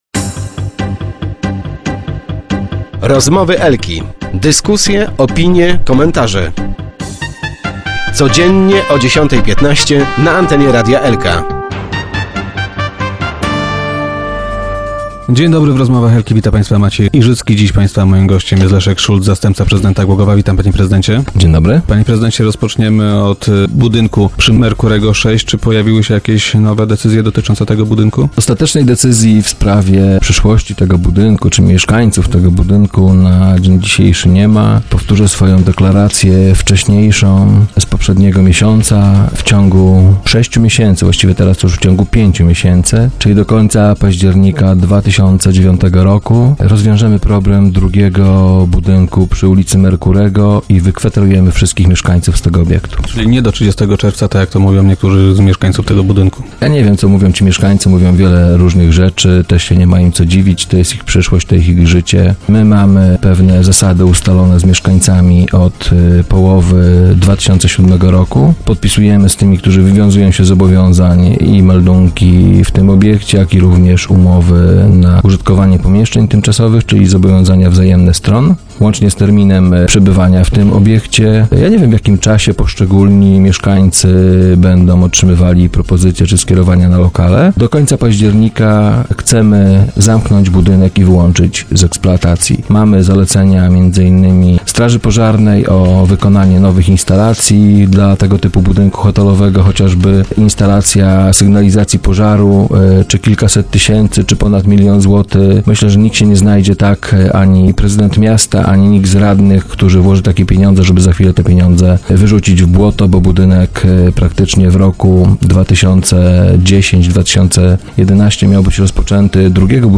- W przyszłym roku chcemy rozpocząć jego przebudowę - poinformował Leszek Szulc, zastępca prezydenta Głogowa, który był dziś gościem Rozmów Elki.